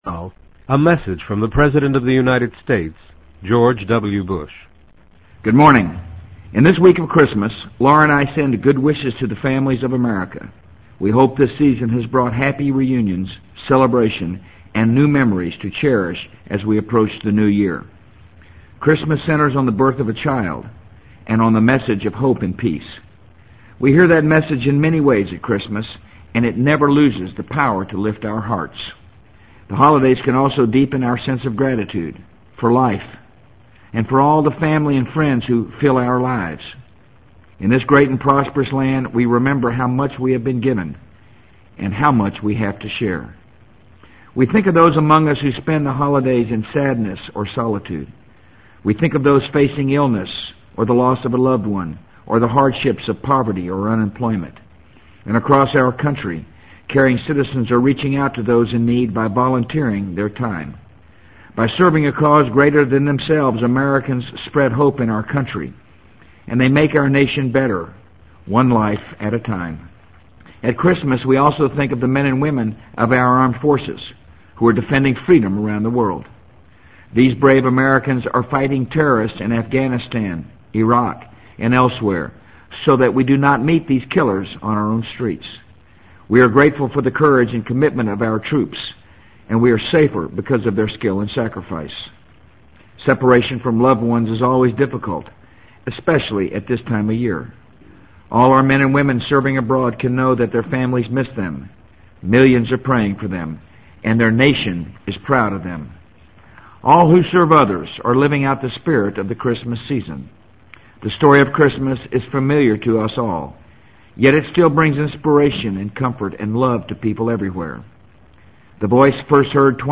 【美国总统George W. Bush电台演讲】2003-12-27 听力文件下载—在线英语听力室